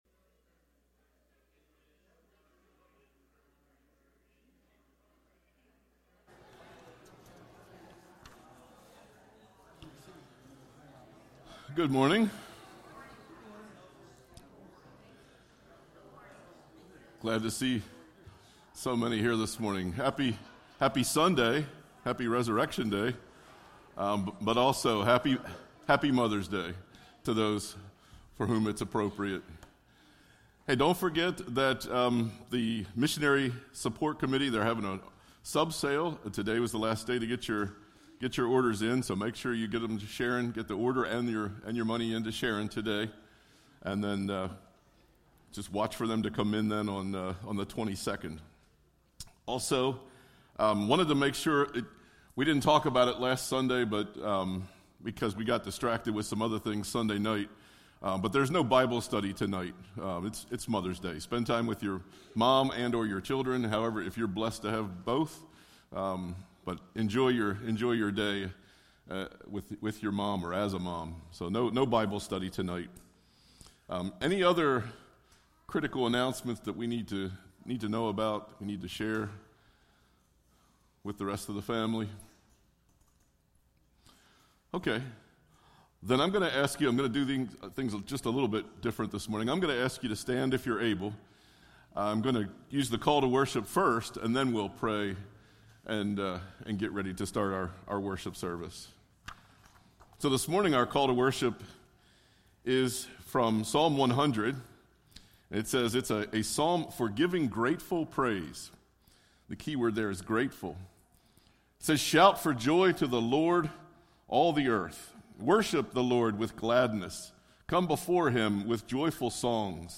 Sermons by Palmyra First EC Church